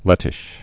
(lĕtĭsh)